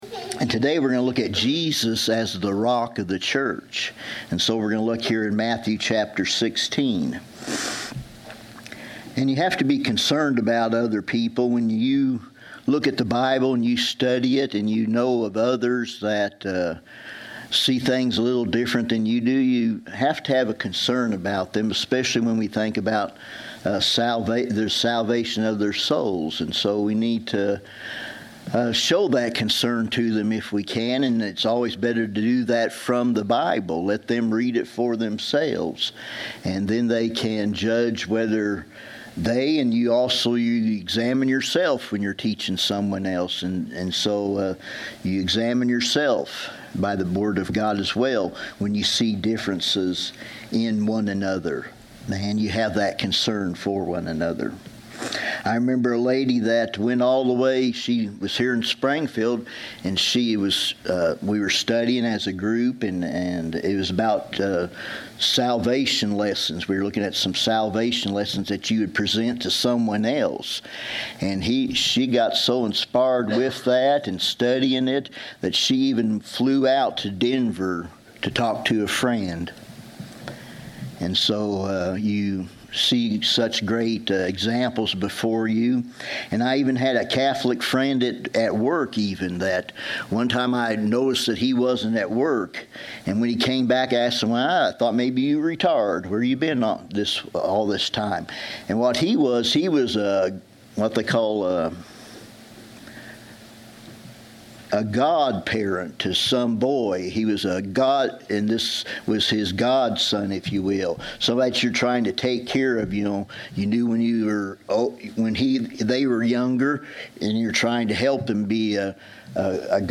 Service Type: Sunday 11:00 AM